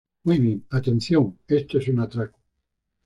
a‧ten‧ción
/atenˈθjon/